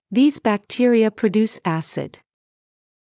ｼﾞｰｽﾞ ﾊﾞｸﾃﾘｱ ﾌﾟﾛﾃﾞｭｰｽ ｱｼｯﾄﾞ